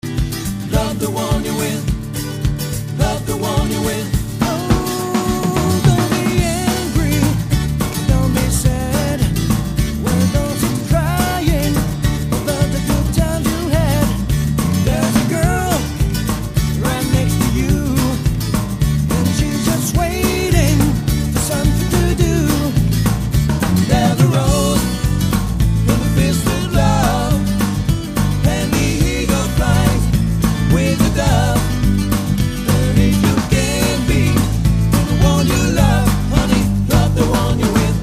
privilégiant les instruments acoustiques.